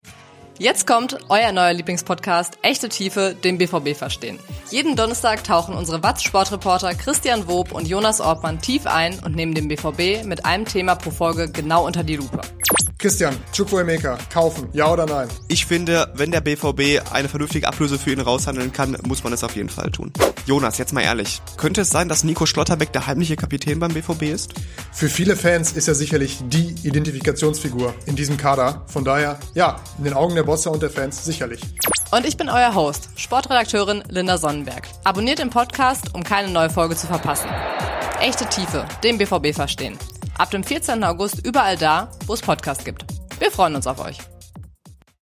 BVB-Reporter diskutieren ein Thema in der Tiefe